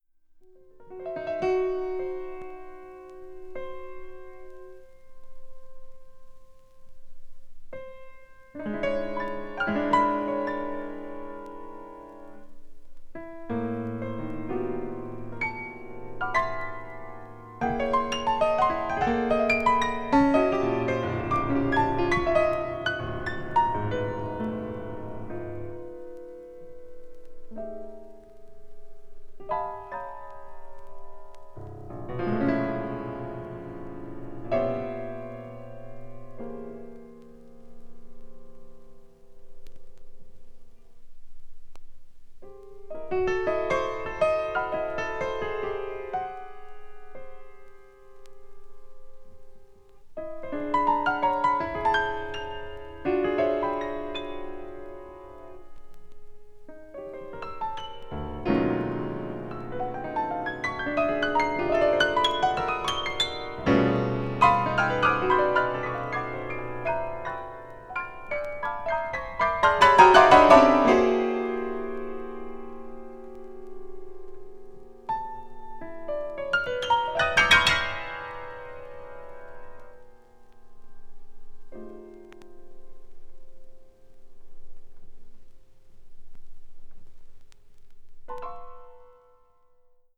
media : VG+/VG+(わずかなチリノイズ/一部軽いチリノイズが入る箇所あり,軽いプチノイズ数回あり)
avant-garde   contemporary   modern classical   post modern